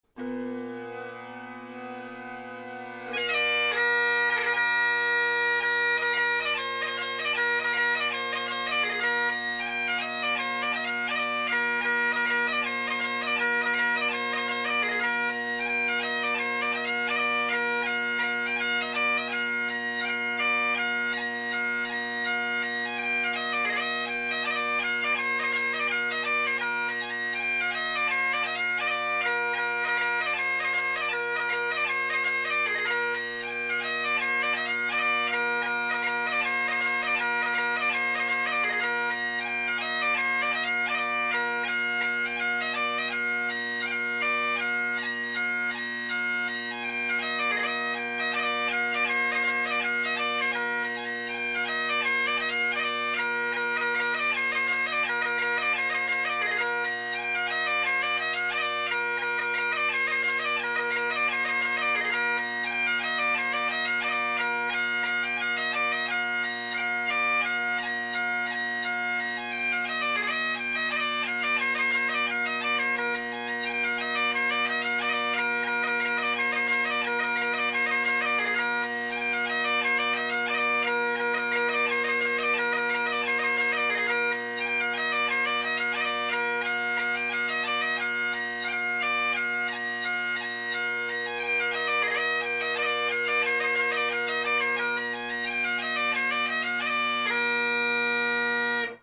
Kate Dalrymple is a great little 2 part reel that reinforces common reel basics.